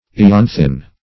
ianthinae.mp3